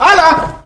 Worms speechbanks
hello.wav